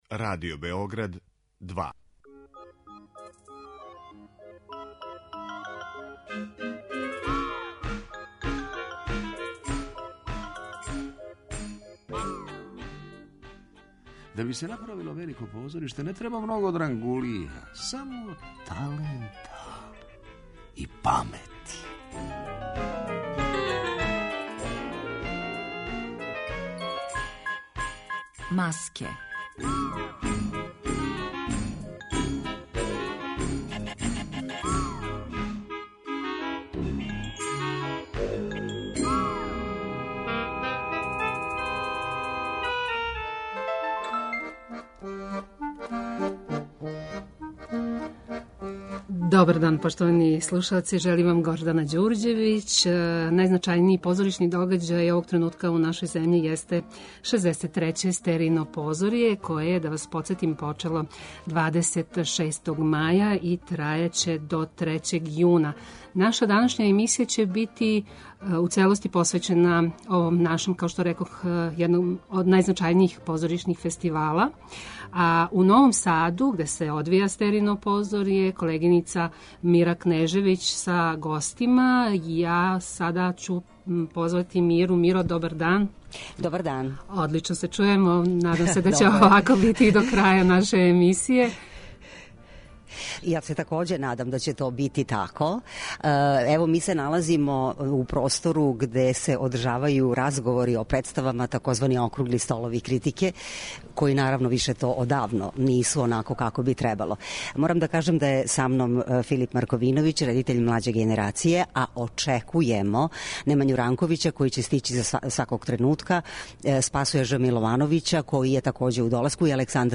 Емисија о позоришту Маске реализује се из Новог Сада и посвећена је 63. Стеријином позорју, које се одржава у овом граду до 3. јуна. Позоришна публика имаће прилику да види девет представа, које ће бити у конкуренцији за Стеријину награду, и три у селекцији Кругови.